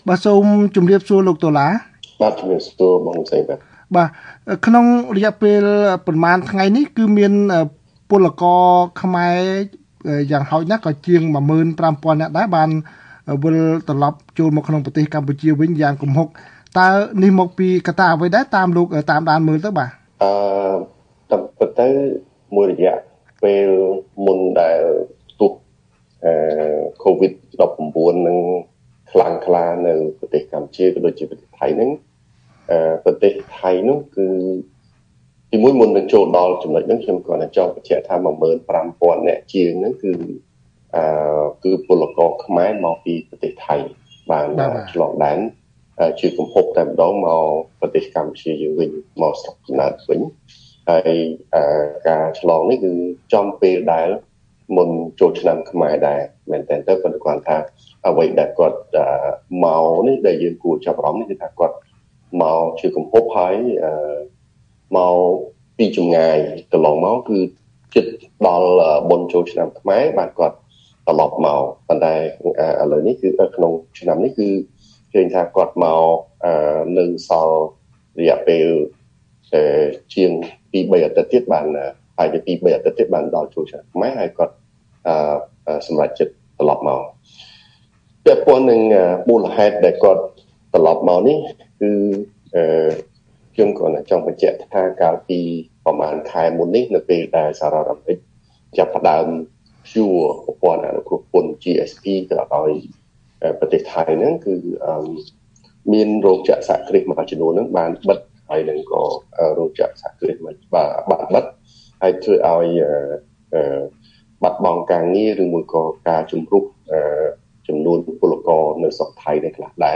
បទសម្ភាសន៍ VOA៖ អ្នកការពារសិទ្ធិថាពលករចំណាកស្រុកដែលវិលពីថៃប្រឈមហានិភ័យសុខភាពនិងសេដ្ឋកិច្ច